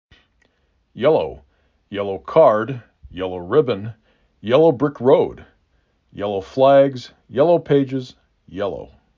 6 Letters, 2 Syllable
4 Phonemes
y e l O